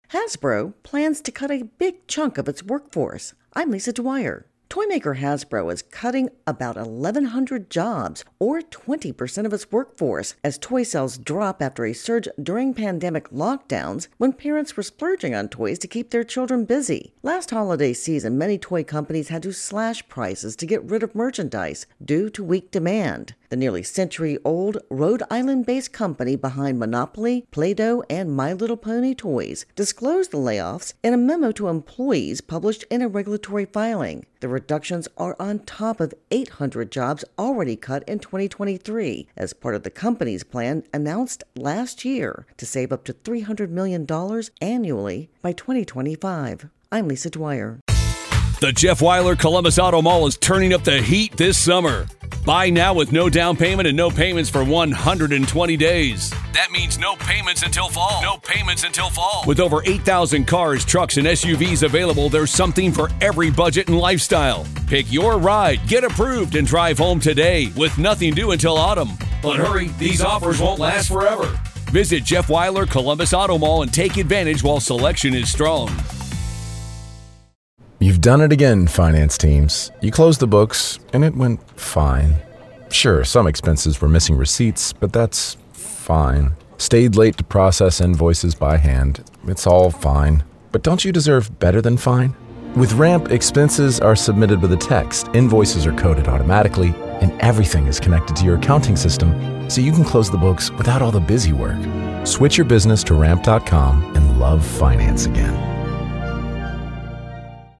reports on Hasbro layoffs.